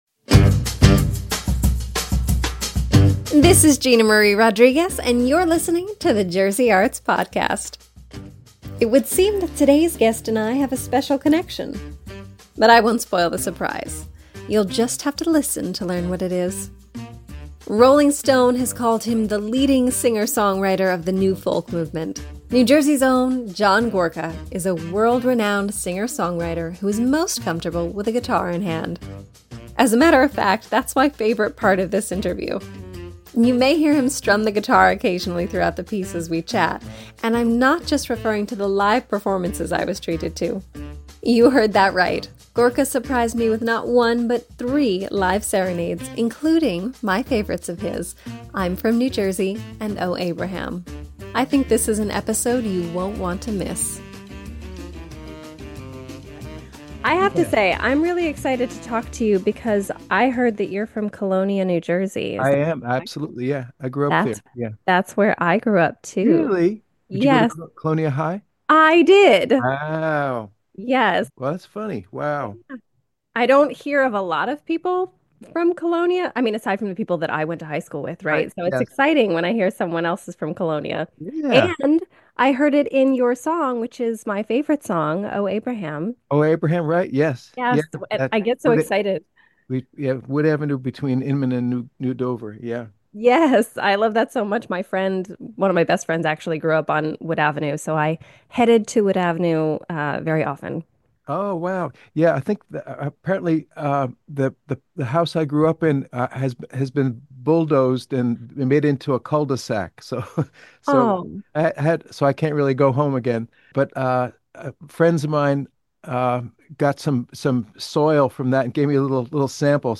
You may hear him strum the guitar occasionally throughout the piece and not just during his live performances! That's right, this episode includes three live serenades, including "I'm From New Jersey" and "Oh Abraham."